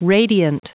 Prononciation du mot radiant en anglais (fichier audio)
Prononciation du mot : radiant